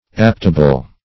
Search Result for " aptable" : The Collaborative International Dictionary of English v.0.48: Aptable \Apt"a*ble\, a. [LL. aptabilis, fr. L. aptare.] Capable of being adapted.